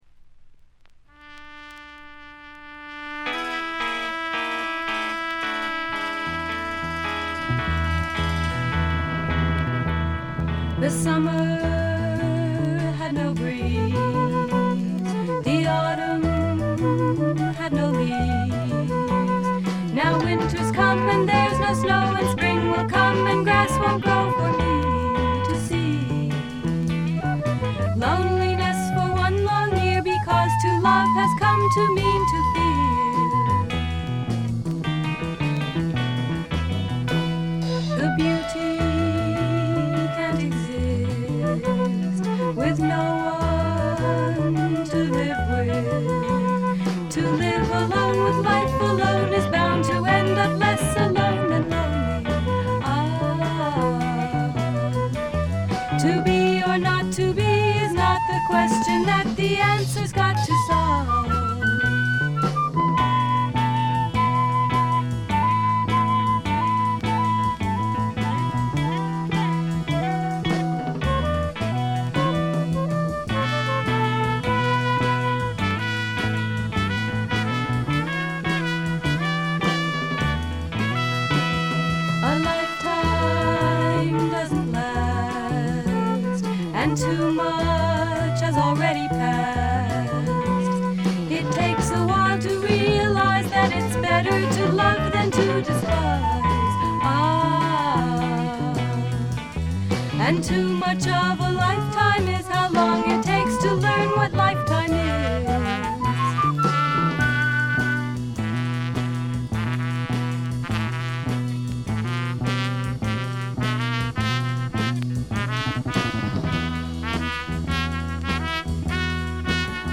昔からアシッドフォークの定番扱いされてきた名盤です。
フォーキーな曲からプログレッシヴでアヴァンギャルドな展開まで、一大サイケデリック絵巻を見せる名作と言ってよいでしょう。
試聴曲は現品からの取り込み音源です。